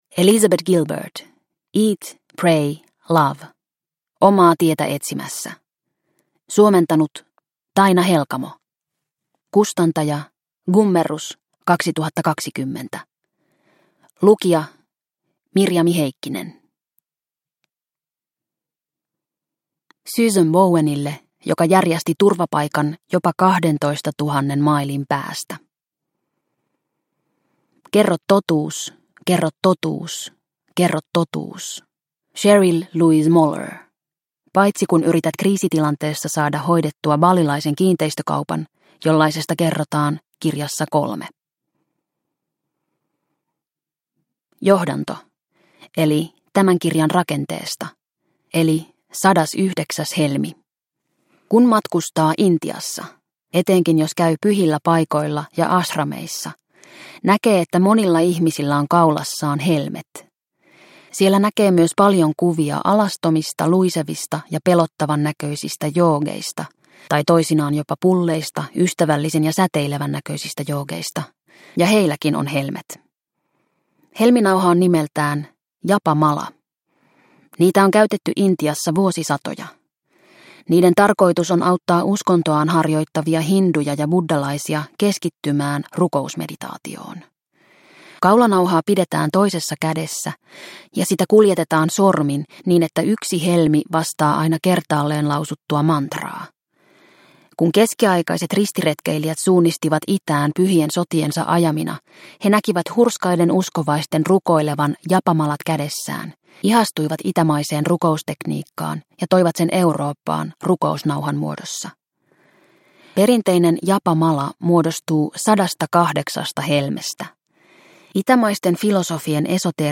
Eat Pray Love – Ljudbok – Laddas ner